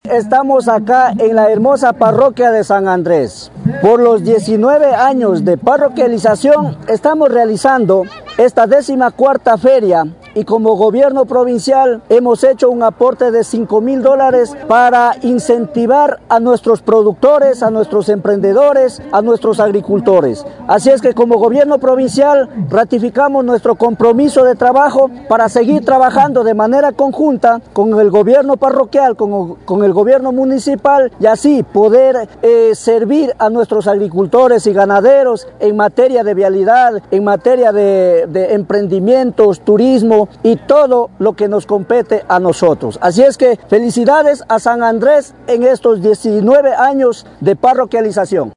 VÍCTOR SARANGO, VICEPREFECTO
VICTOR-SARANGO-VICEPREFECTO.mp3